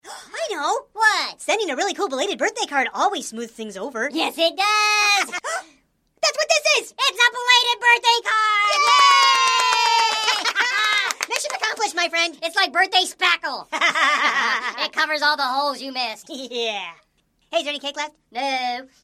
Soooo Sorry is a hoops&yoyo belated birthday greeting card with sound.
Card sound